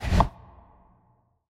slide.mp3